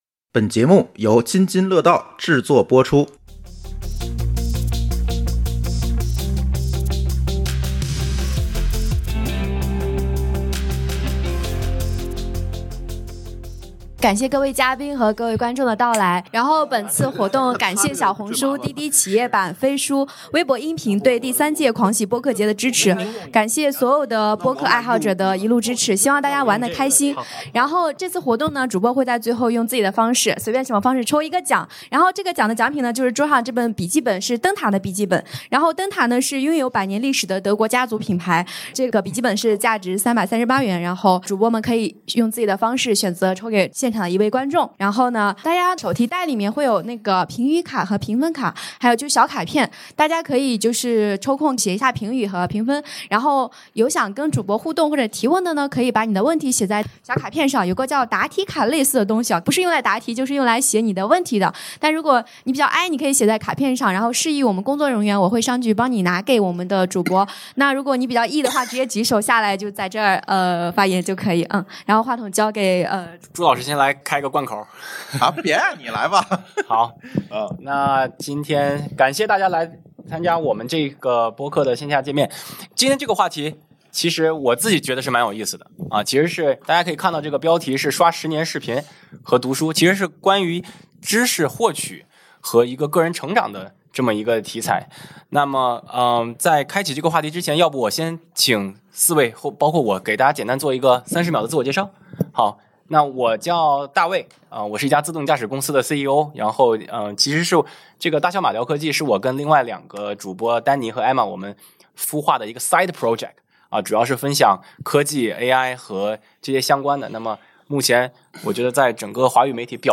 本次播客录制于#贰狂播客节